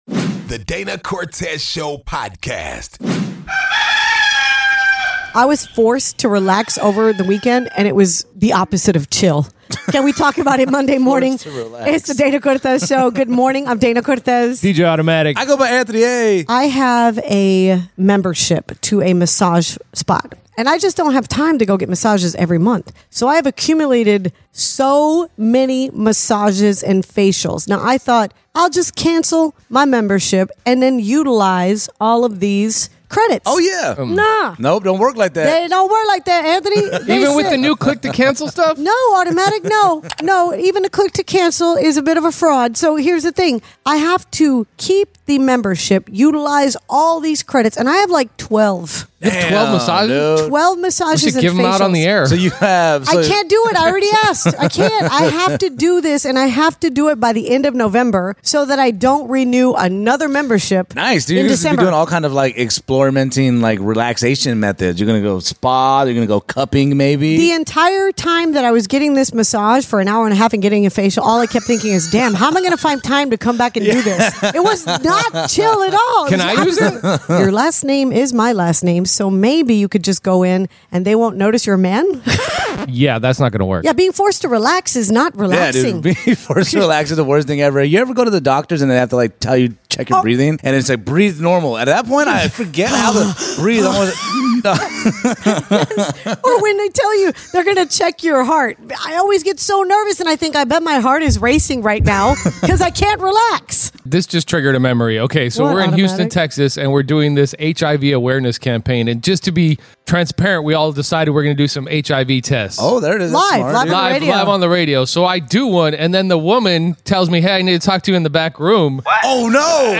If that wasn't crazy enough we got a call from another listener doubling down on it.